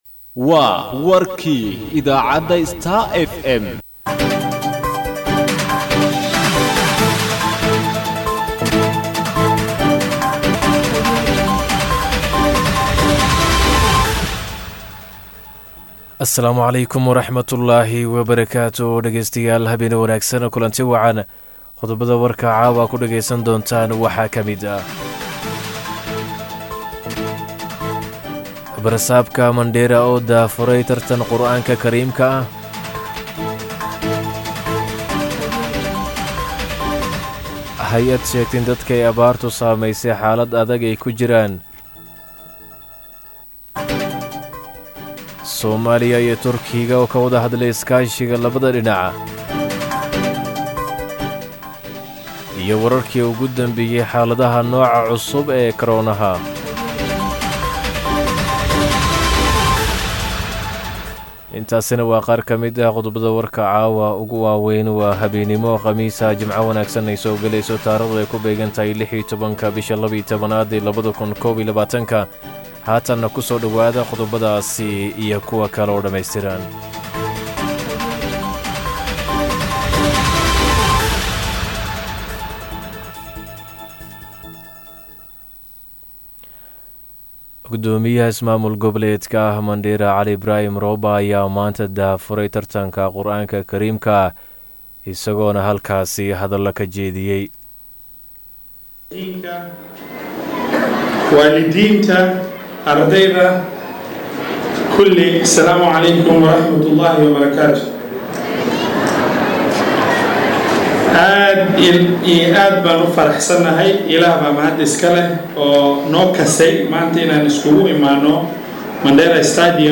DHAGEYSO:WARKA HABEENIMO EE IDAACADDA STAR FM